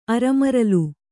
♪ aramaralu